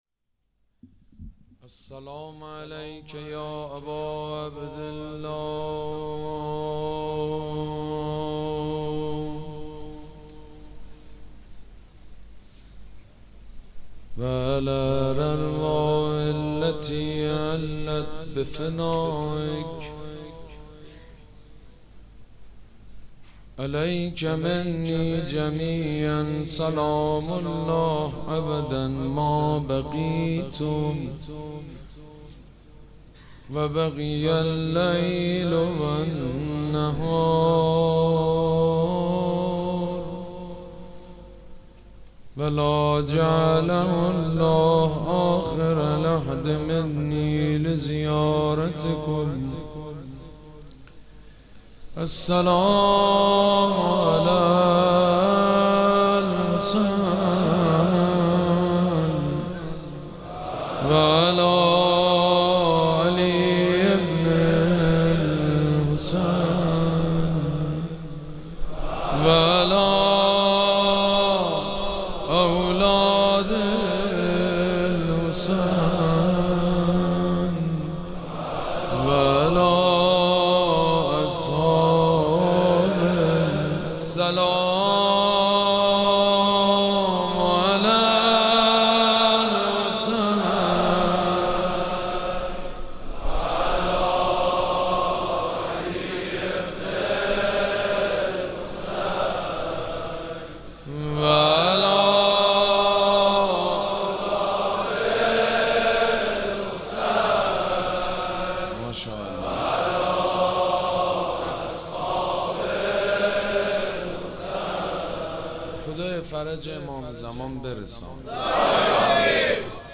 مراسم عزاداری شب ششم محرم الحرام ۱۴۴۷ سه‌شنبه ۱۰تیر۱۴۰۴ | ۵ محرم‌الحرام ۱۴۴۷ هیئت ریحانه الحسین سلام الله علیها
شعر خوانی